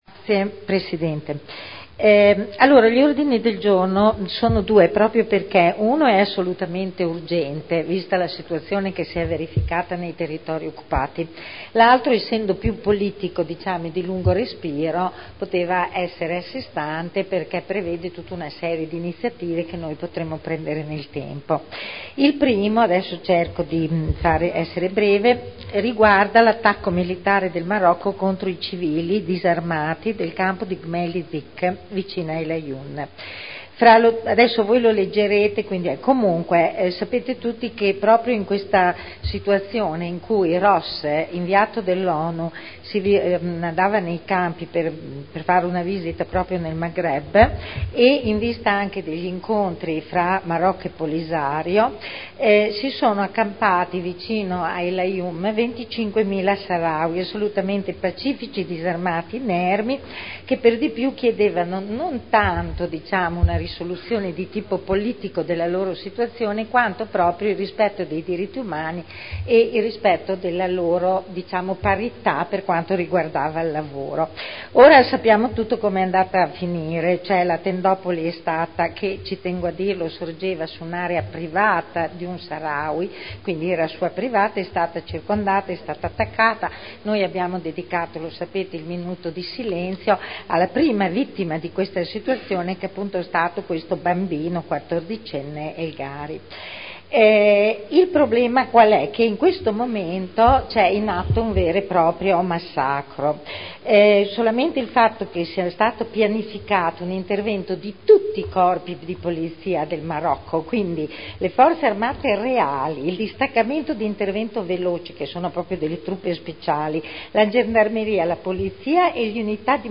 Seduta del 15/11/2010. Introduce due nuovi Ordini del Giorno non iscritti su "Marocco" e "Popoli del Saharawi"